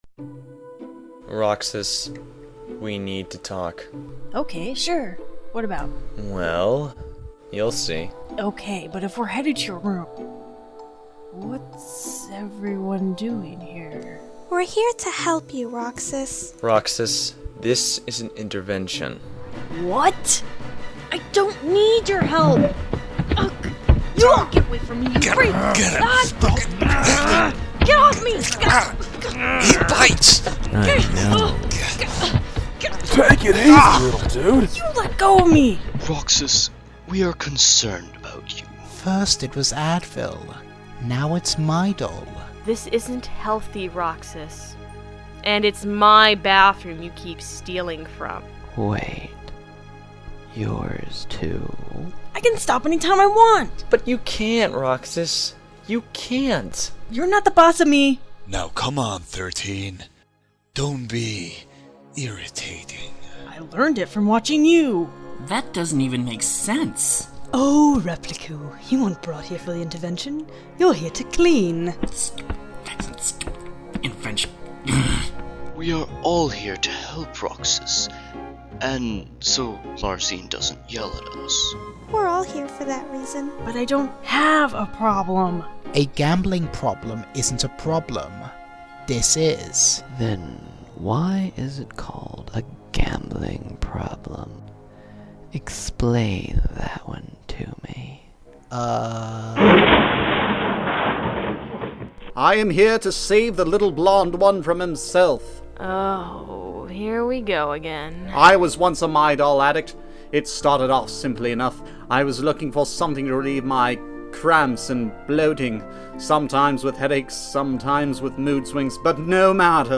Short Dramas